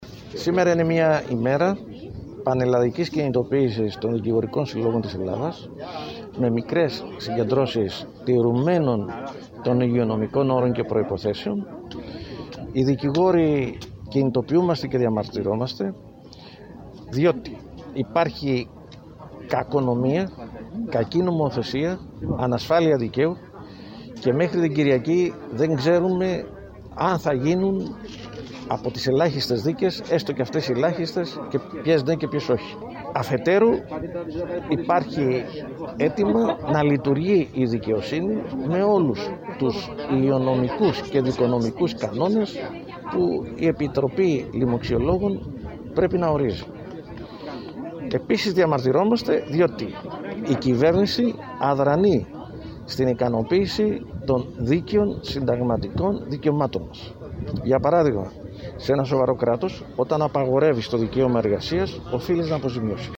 Περιφερειακοί σταθμοί ΚΕΡΚΥΡΑ